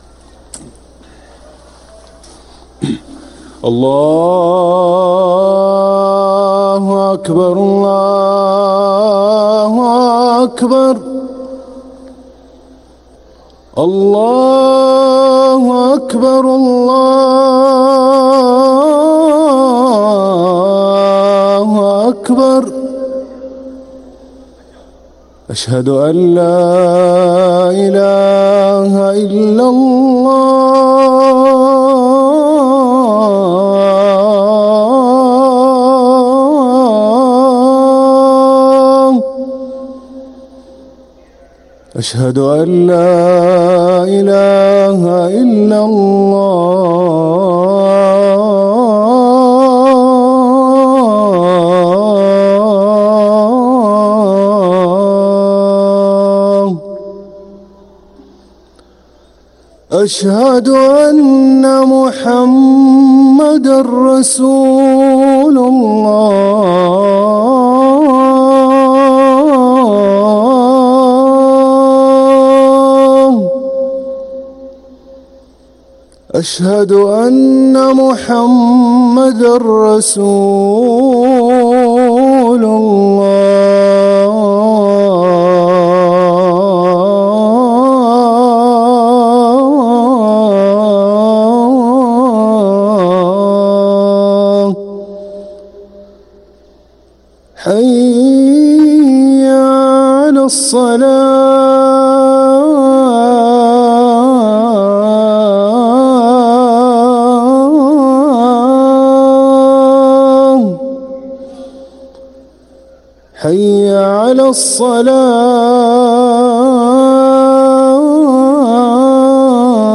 أذان العصر للمؤذن هاشم السقاف الأحد 21 صفر 1446هـ > ١٤٤٦ 🕋 > ركن الأذان 🕋 > المزيد - تلاوات الحرمين